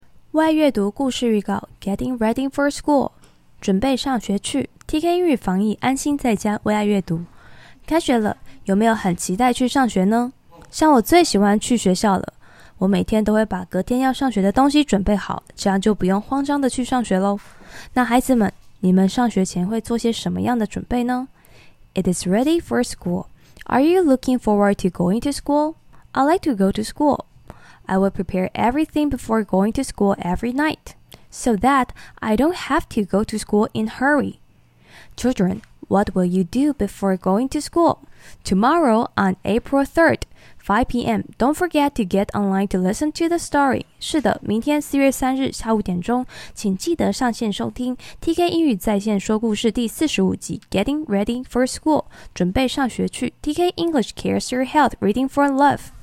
导读音频：